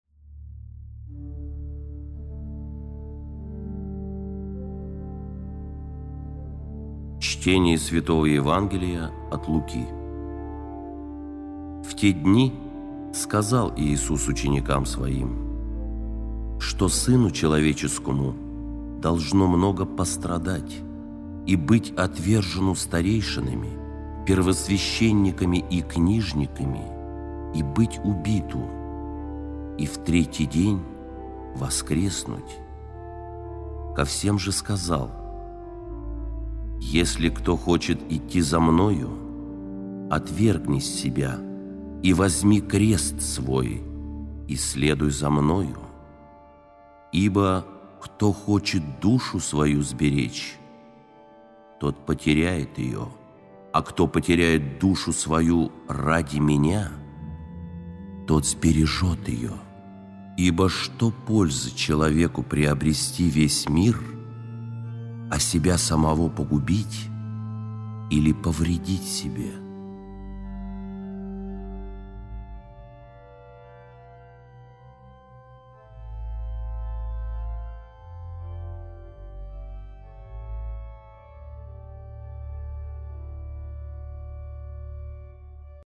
Текст читает: